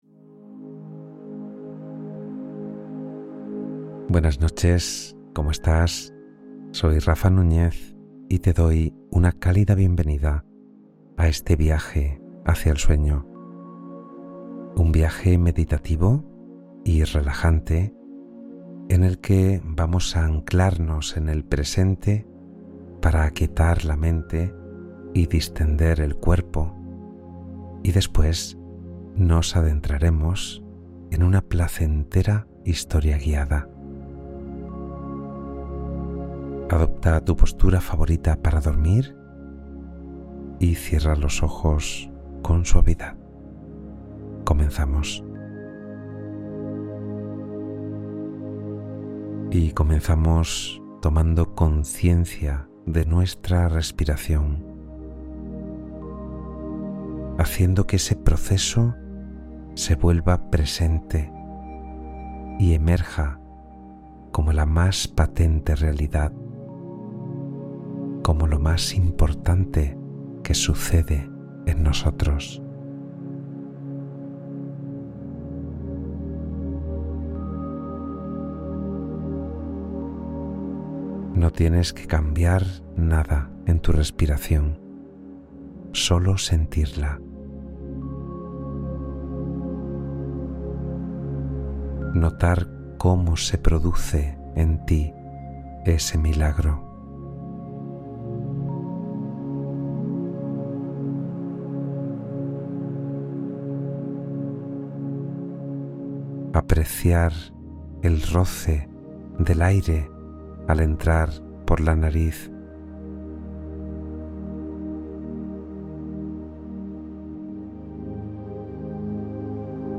Sueño Profundo: Meditación Guiada para Descansar